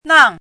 chinese-voice - 汉字语音库
nang4.mp3